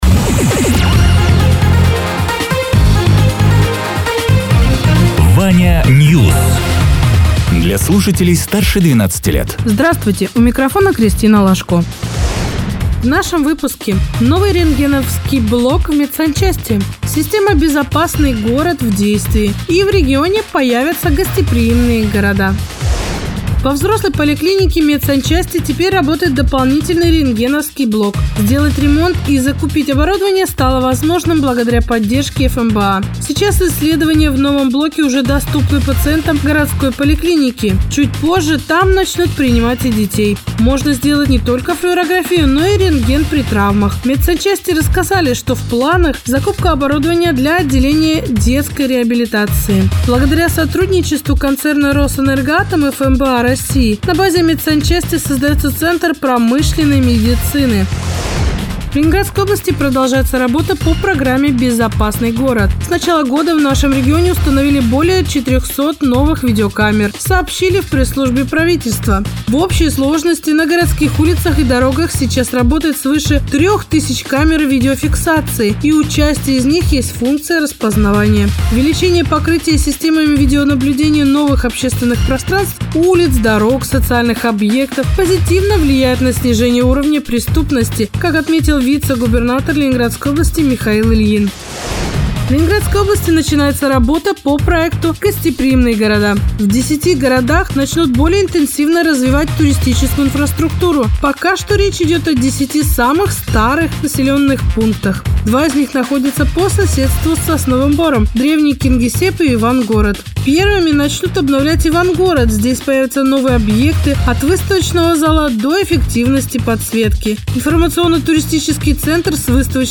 Радио ТЕРА 25.11.2024_10.00_Новости_Соснового_Бора